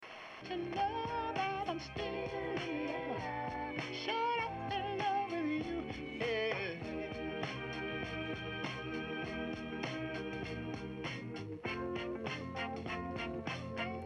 After four seek tests for each antenna I set the radio to a distant Los Angeles station that was too weak to be automatically locked onto by any of the three and recorded the signal and variance (fuzz in/out of sound) as I walked around the bike.
Remember this was a distant weak signal you'd normally never listen to because the sound is a bit fuzzy with all the antennas tested.
Whip 25 Consistently Very Good with a variance in one spot when I walked around the bike
antenna_test_whip_reception.mp3